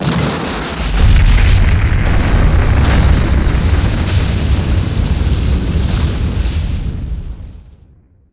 1 channel
Buildingblast4.mp3